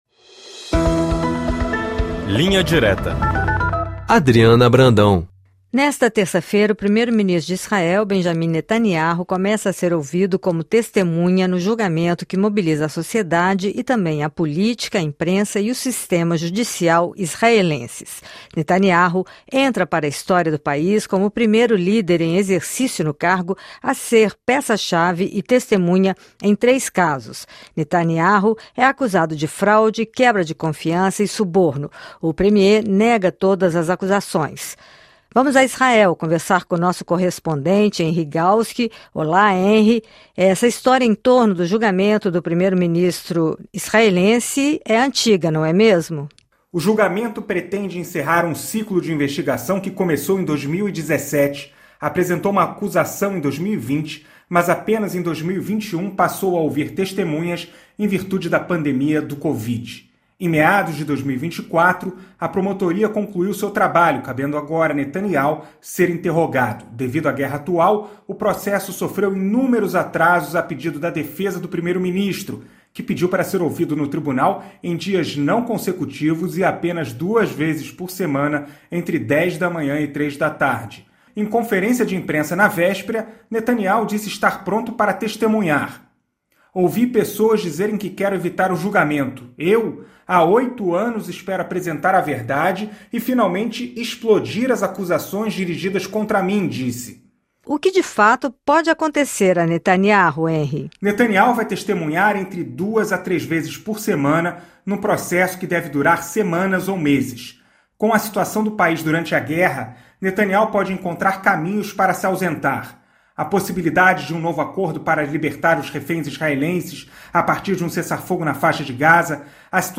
correspondente da RFI em Israel